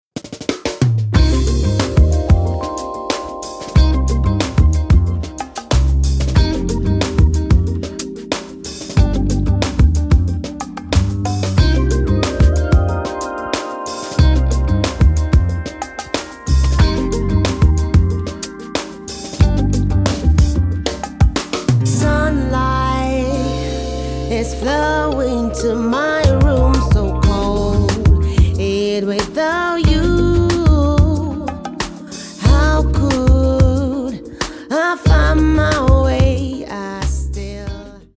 Вложения I_Miss_You_Beta_Mix_Vocal_Guitar_Solo_Short.mp3 I_Miss_You_Beta_Mix_Vocal_Guitar_Solo_Short.mp3 894,2 KB · Просмотры: 144